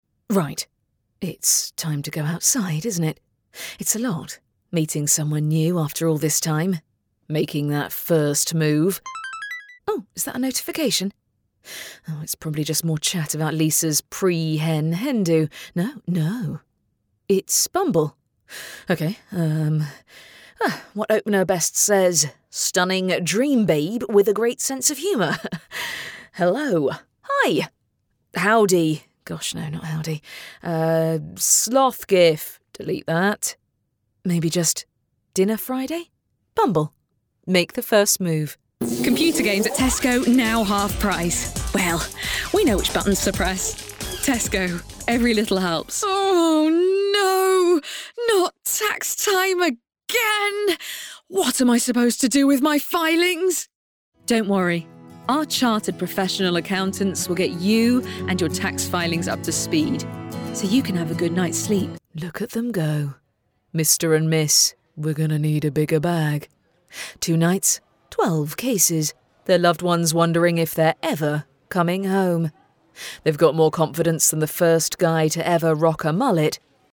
Commercial Demo
Professionally built studio.
Mezzo-Soprano
WarmConversationalFriendlyClearProfessionalConfidentWitty